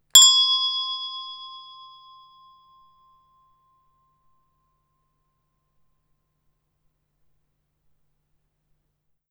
chime
low.wav